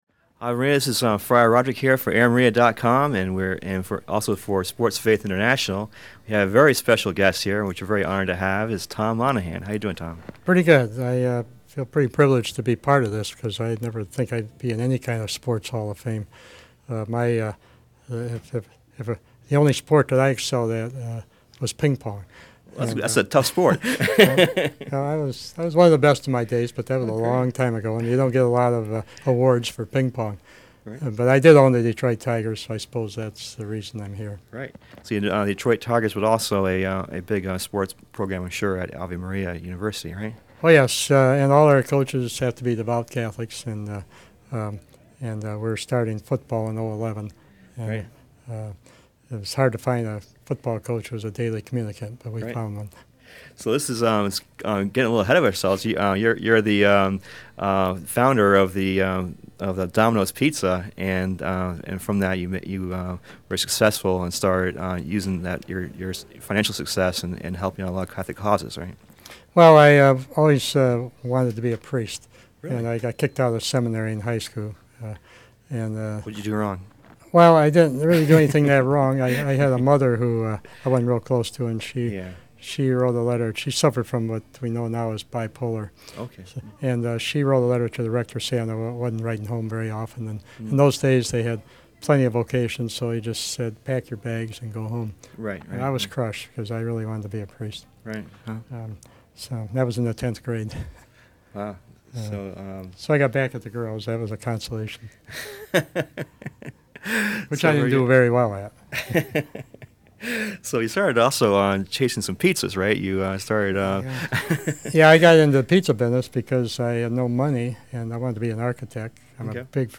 interviews Tom Monaghan, founder of Domino's Pizza, while he was in the Chicago area to be inducted into the Sports Faith International Hall of Fame as former owner of the Major League Baseball team Detroit Tigers. He talks about how he started Domino's Pizza and how important faith is to his motivation and how he wants to use his wealth to revive Catholic higher education. He gives insight into the importance of sports in our lives for building character.